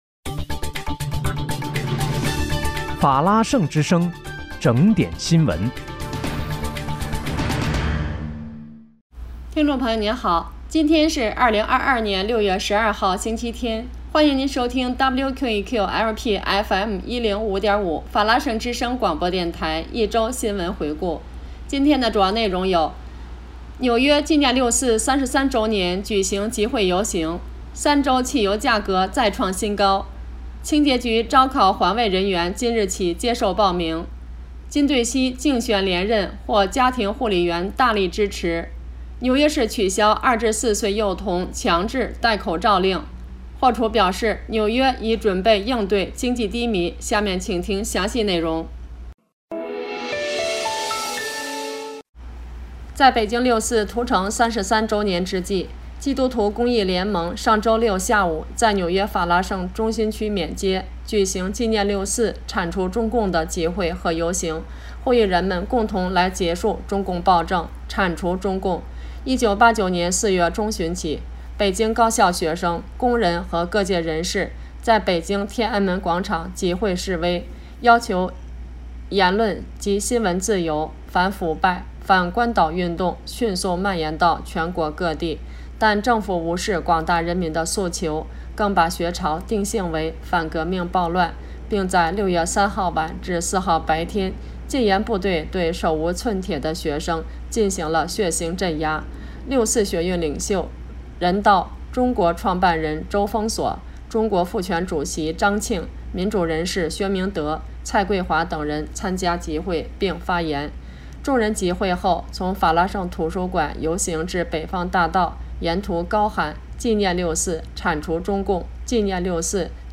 6月12日（星期日）一周新闻回顾